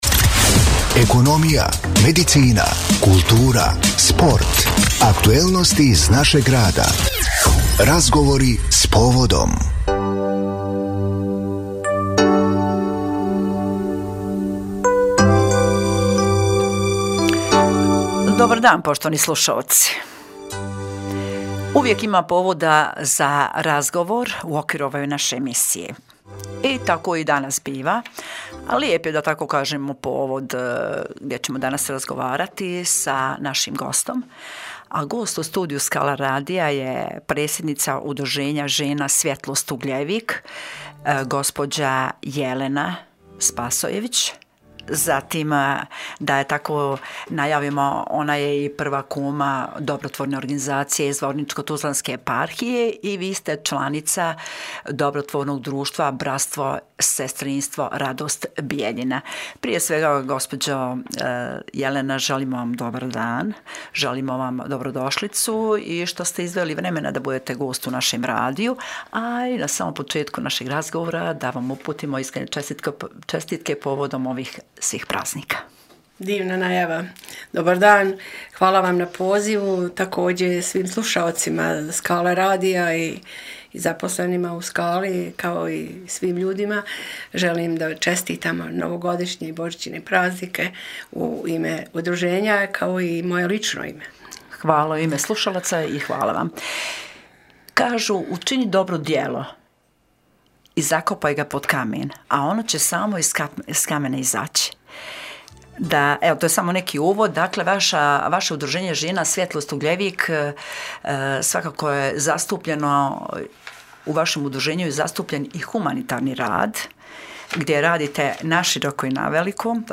GOST U STUDIJU SKALA RADIJA